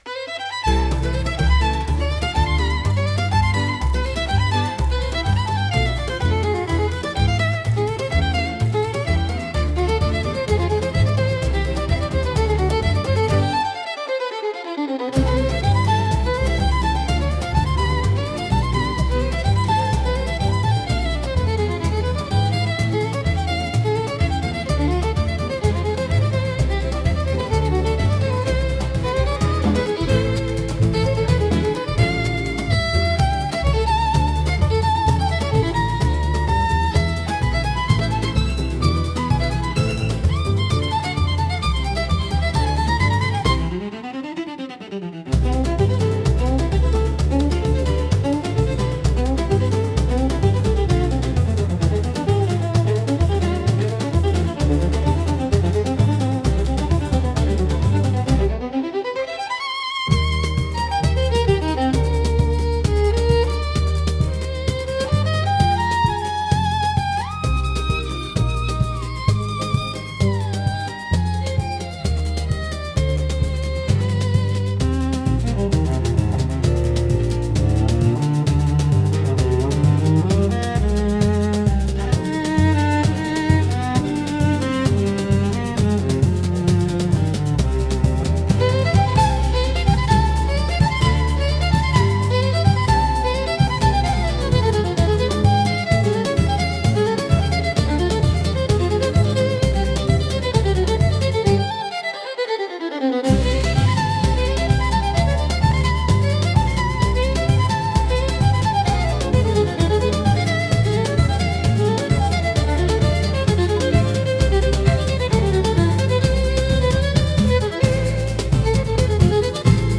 música e arranjo IA) instrumental